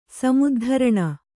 ♪ samuddharaṇa